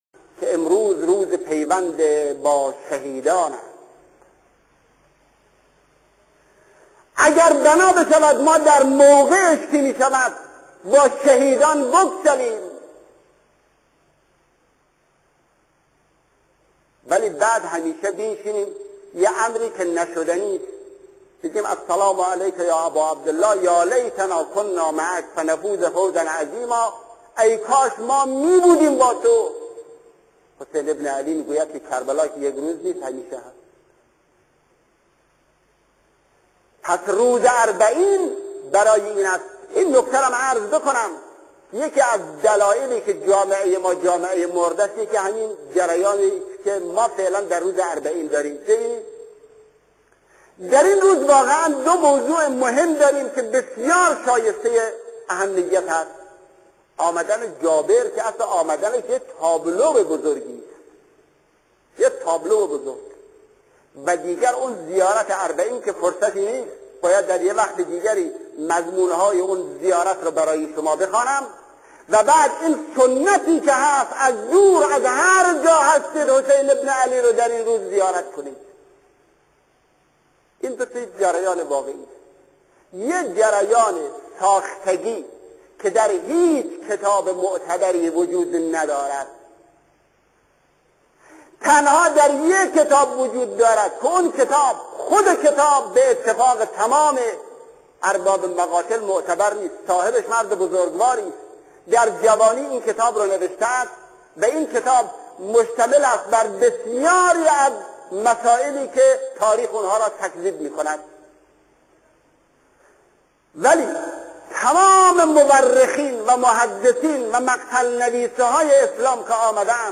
سخنرانی شهید مطهری به مناسبت اربعین امام حسین (ع)+صوت